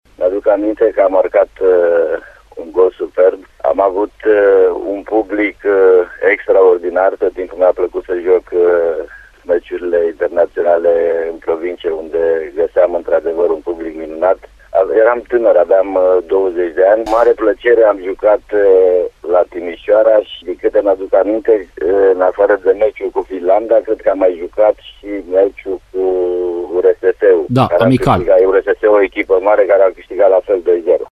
Îl ascultăm pe fostul jucător al Corvinului Hunedoara, Dorin Mateuţ, într-o declaraţie pentru Radio Timişoara.